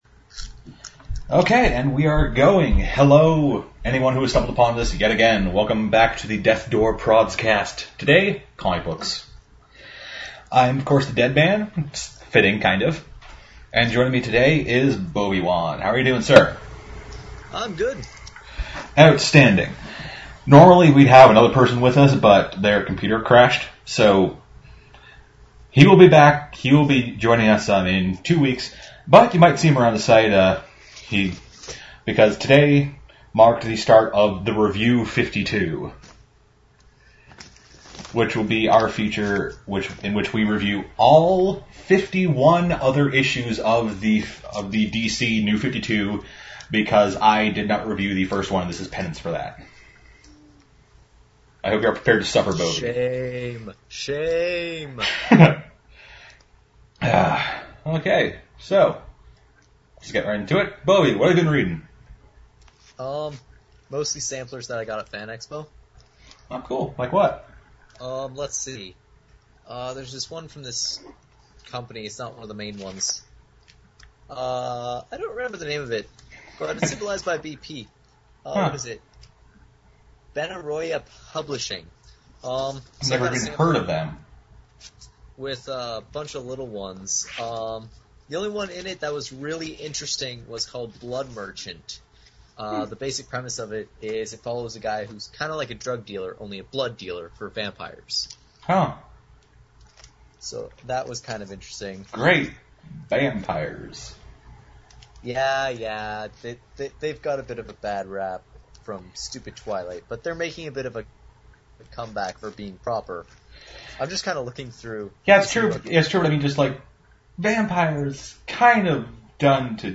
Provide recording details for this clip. (We apologize for the difference in audio levels. We’re working on it.)